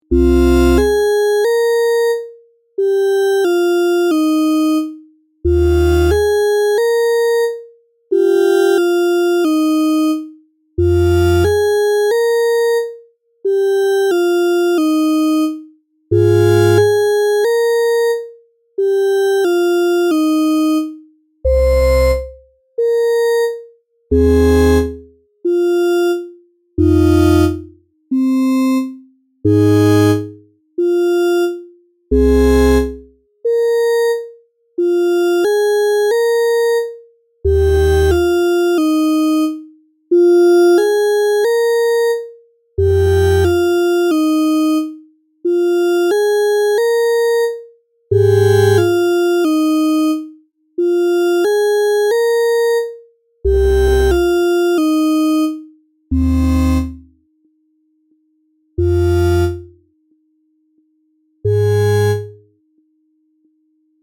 SFディストピアなイメージ。ループ対応。
BPM90